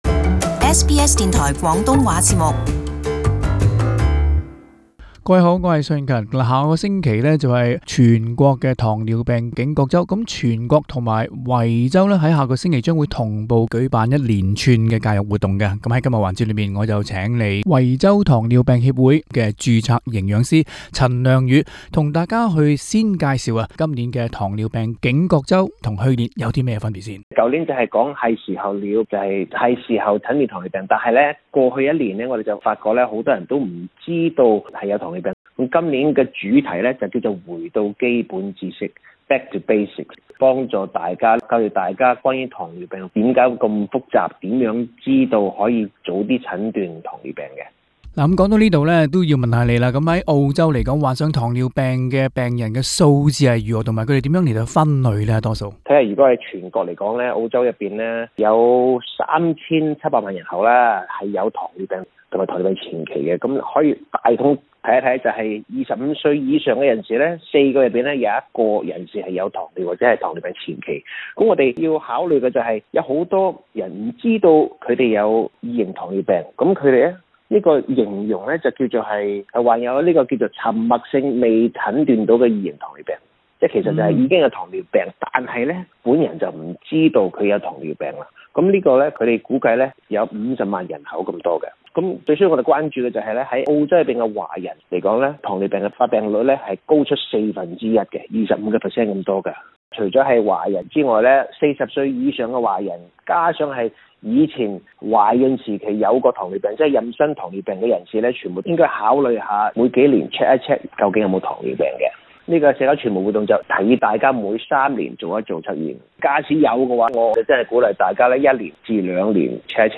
【社區專訪】回歸基本-認識糖尿病